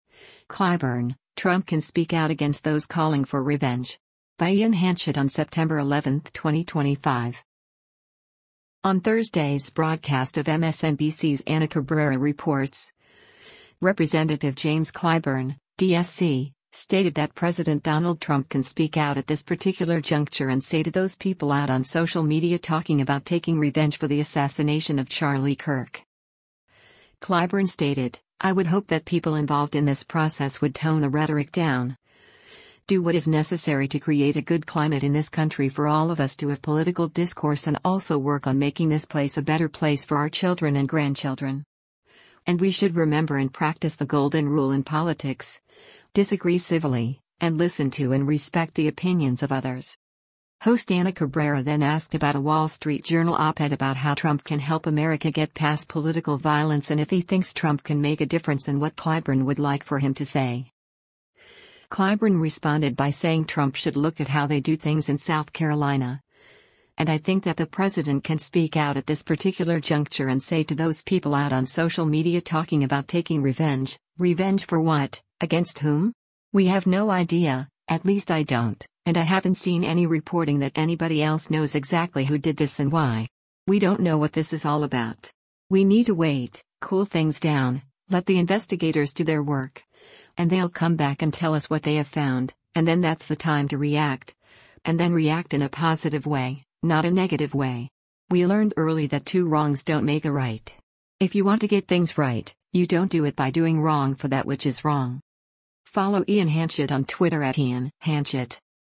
Video Source: MSNBC
Host Ana Cabrera then asked about a Wall Street Journal op-ed about how Trump can help America get past political violence and if he thinks Trump can make a difference and what Clyburn would like for him to say.